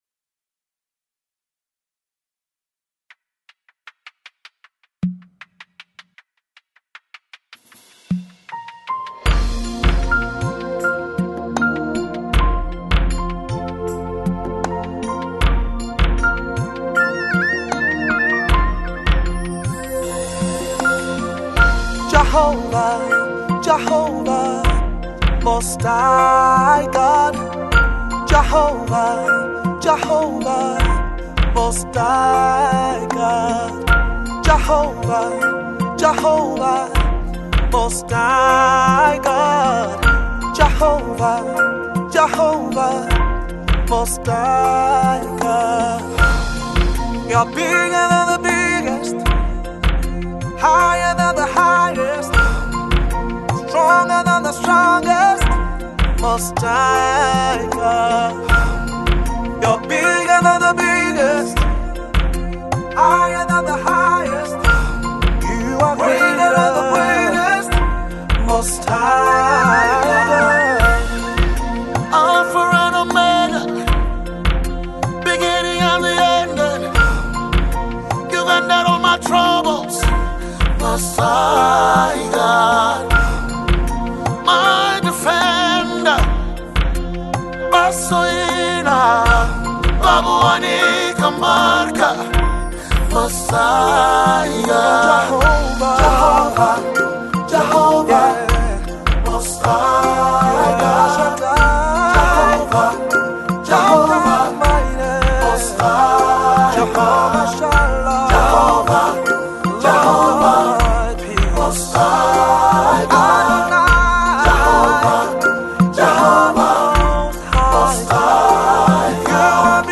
Gospel Music
Gospel Music Genre: Afrobeats Released